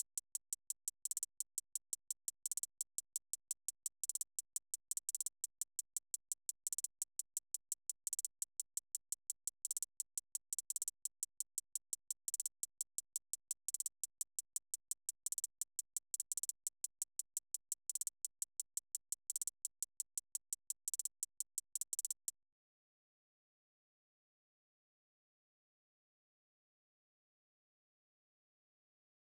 diamond rings of saturn - 171bpm_Metro Low Hihat.wav